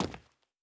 added stepping sounds
PavementTiles_Mono_02.wav